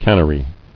[can·ner·y]